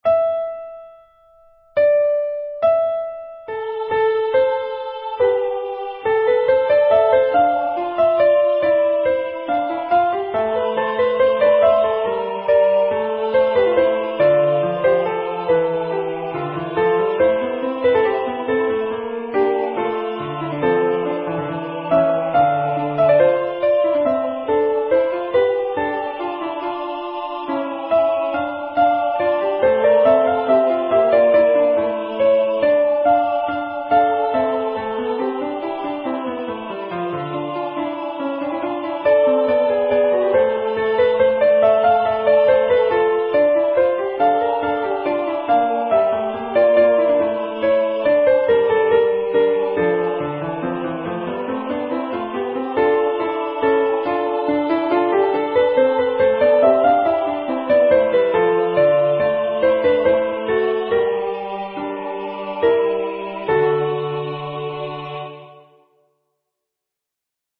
MP3 Practice Files: Soprano:
Genre: SacredMotet
ResurrexiSoprP.mp3